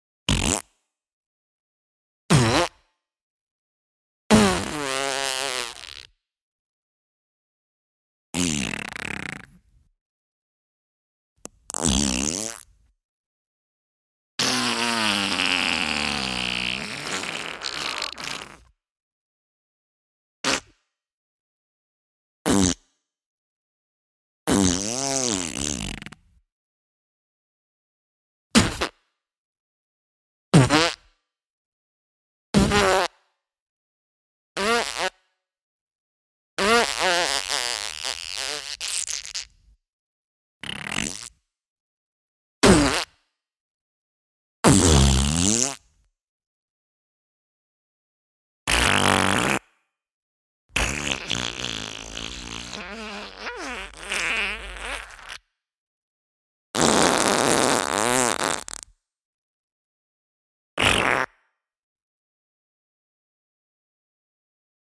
9. یک دقیقه گوز
Fart-Sound-5.mp3